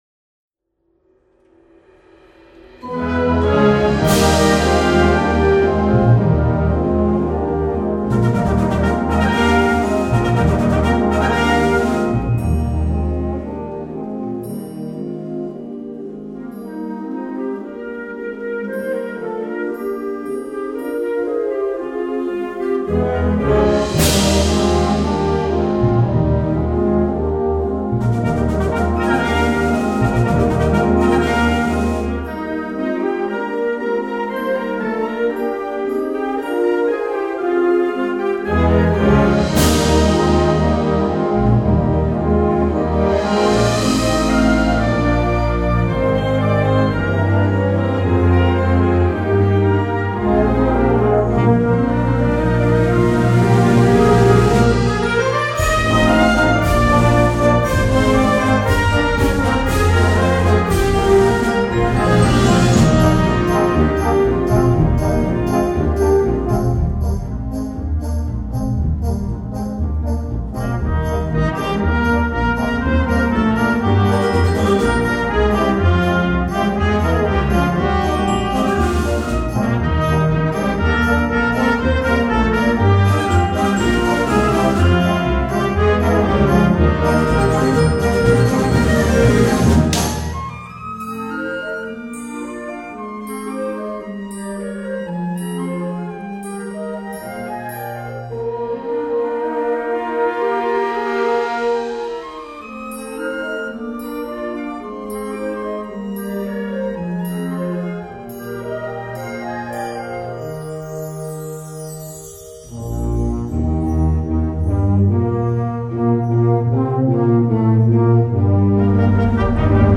Categoria Concert/wind/brass band
Sottocategoria Musica per concerti
Instrumentation Ha (orchestra di strumenti a faito)